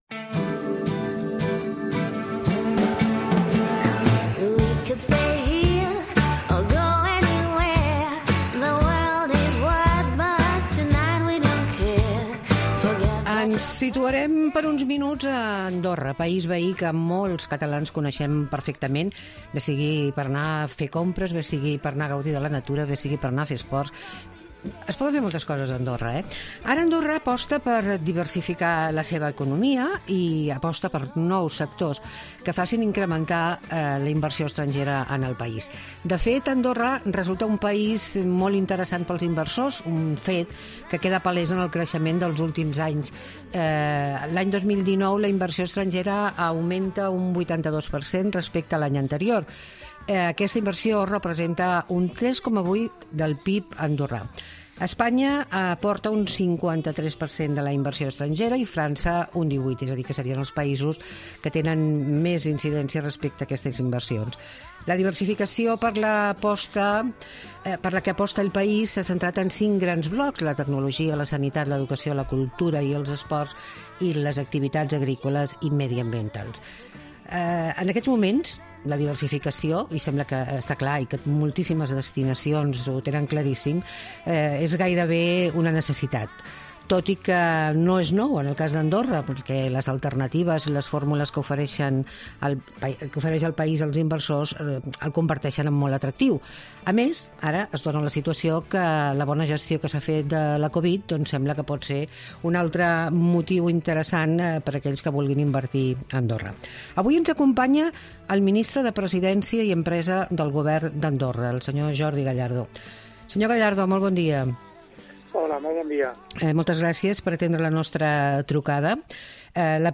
Andorra aposta per diversificar la seva oferta, ens ho explica Jordi Gallardo, Ministre de Presidència i Empre